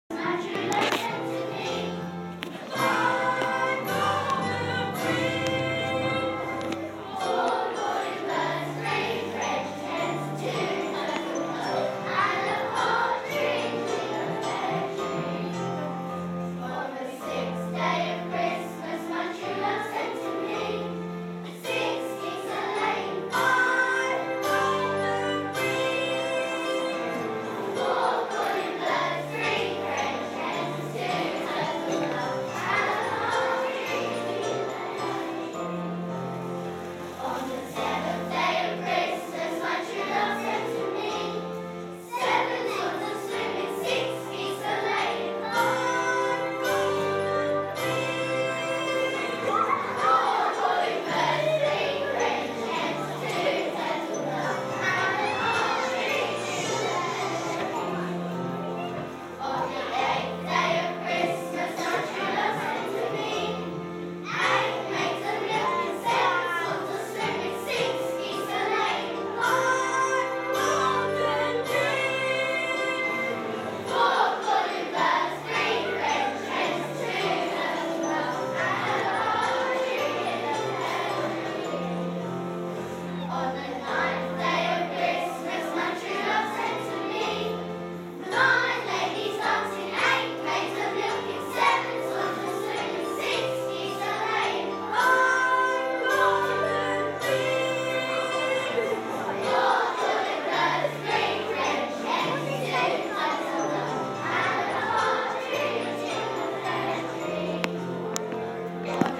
KS2 Choir